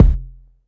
stay_kick_sub.wav